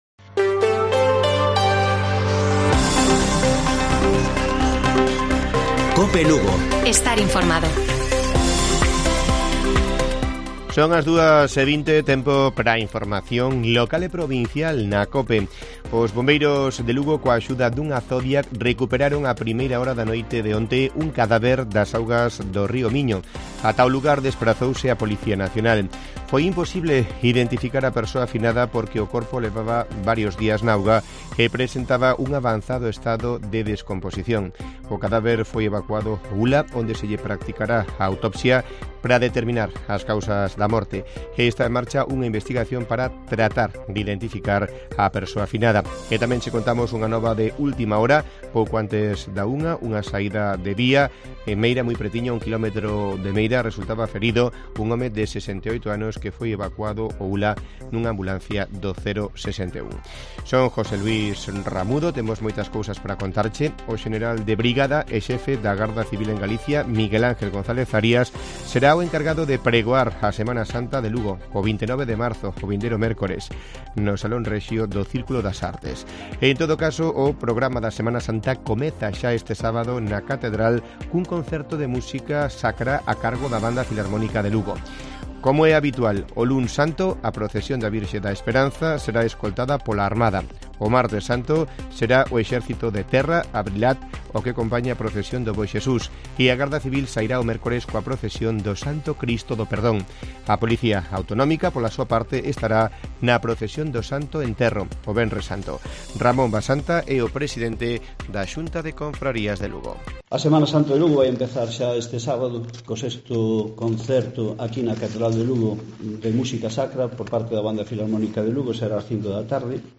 Informativo Mediodía de Cope Lugo. 22 de marzo. 14:20 horas